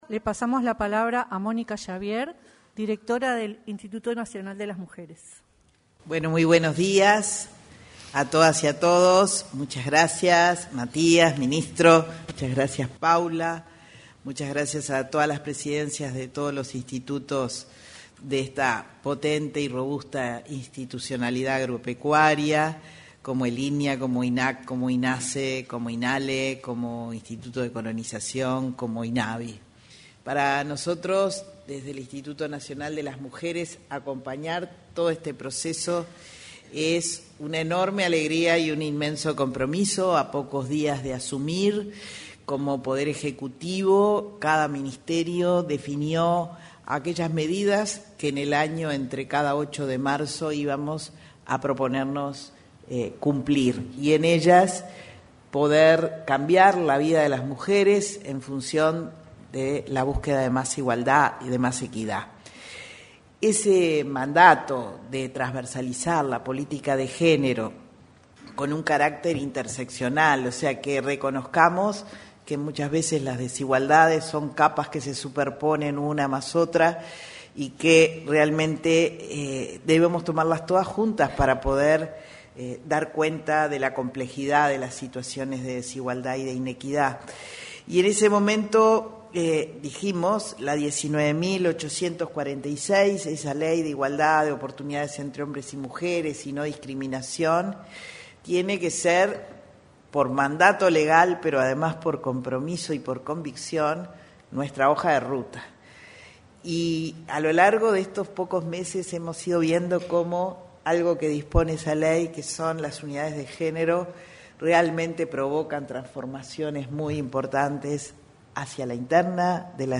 Presentación de política de género agro y plan de acción quinquenal 01/10/2025 Compartir Facebook X Copiar enlace WhatsApp LinkedIn Se realizó, en el auditorio de la Torre Ejecutiva, la presentación de la política de género agro y su plan de acción para 2025-2029. En la oportunidad, se expresaron la directora del Instituto Nacional de las Mujeres, Mónica Xavier, y el ministro interino de Ganadería, Agricultura y Pesca, Matías Carámbula.